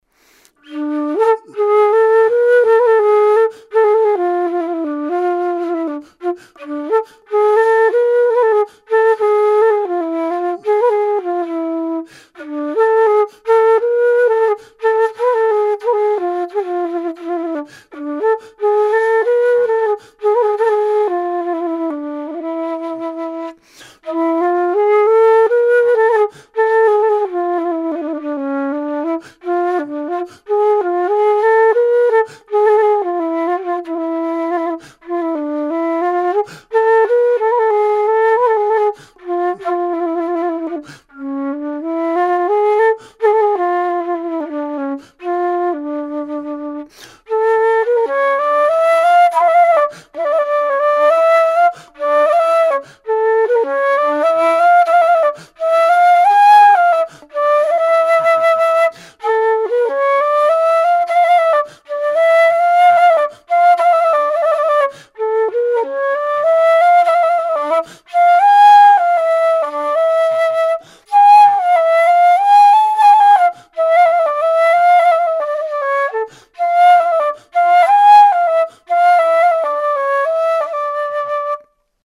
Low D Gypsy flute
GypsyD-impro9.mp3